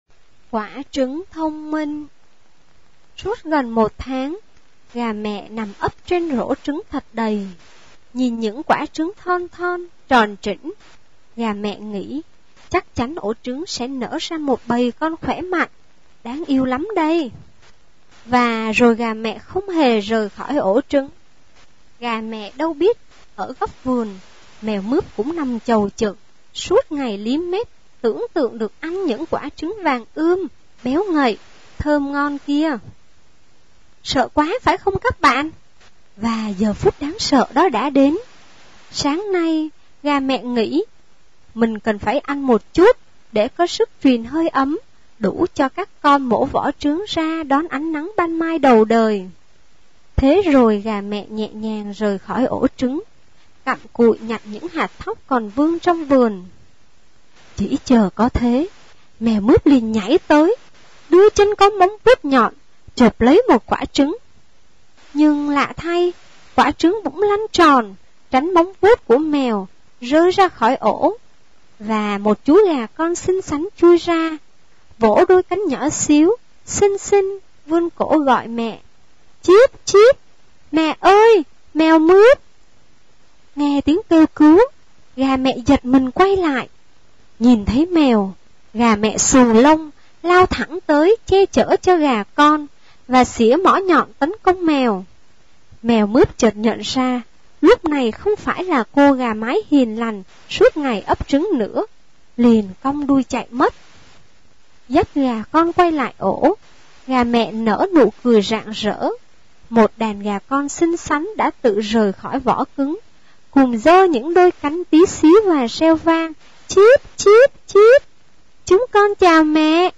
Sách nói | Quả trứng thông minh